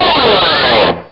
Slide(pick) Sound Effect
slide-pick.mp3